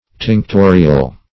Search Result for " tinctorial" : The Collaborative International Dictionary of English v.0.48: Tinctorial \Tinc*to"ri*al\, a. [L. tinctorius, from tinctor a dyer, tingere, tinctum, to dye: cf. F. tinctorial.